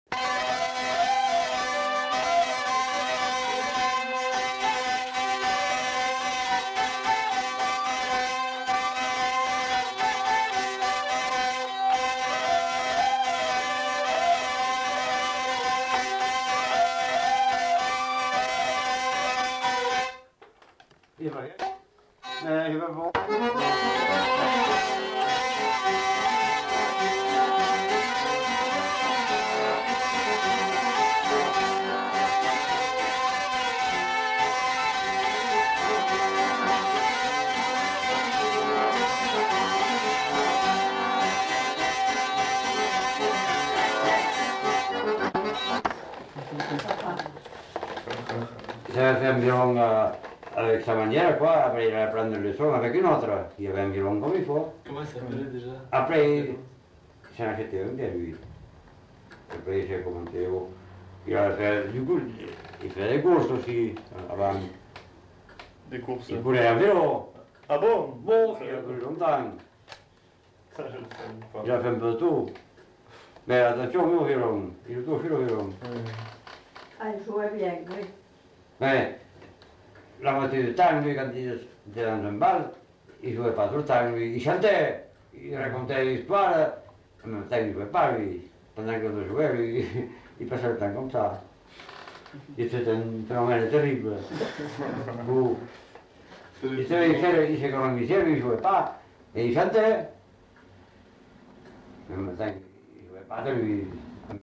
Aire culturelle : Gabardan
Lieu : Herré
Genre : morceau instrumental
Instrument de musique : vielle à roue ; accordéon diatonique
Danse : rondeau